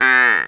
ANIMAL_Duck_02_mono.wav